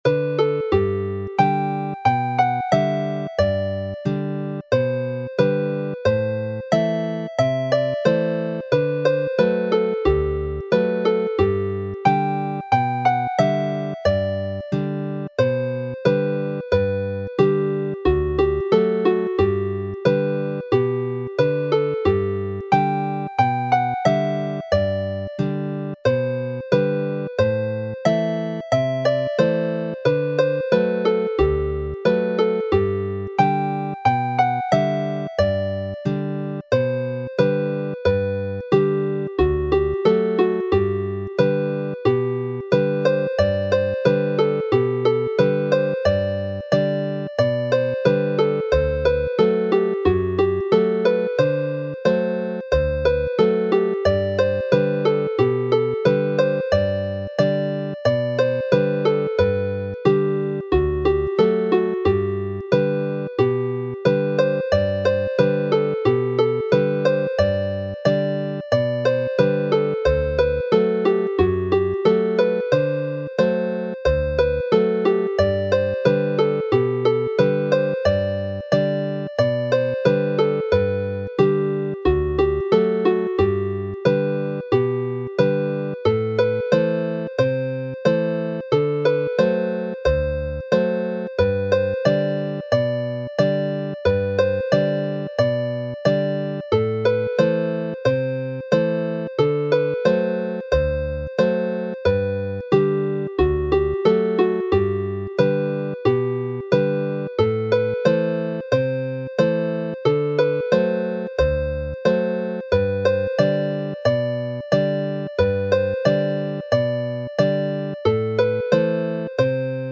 Chwarae'n araf
Play slowly